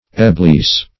(Islam) the principal evil jinni in Islamic mythology ; The Collaborative International Dictionary of English v.0.48: Eblis \Eb"lis\, n. [Ar. iblis.]